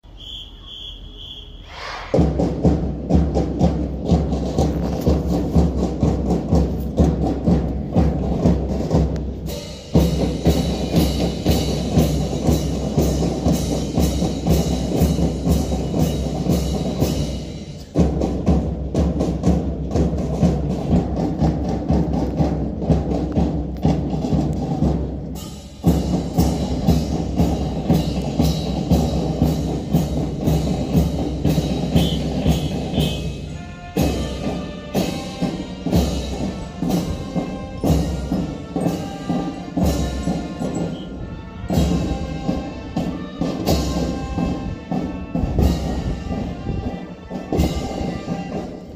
1017♪演奏♪61代長松小鼓笛隊 演奏
鼓笛の総仕上げ練習風景でした。
♪ドラムマーチから校歌1017-.mp3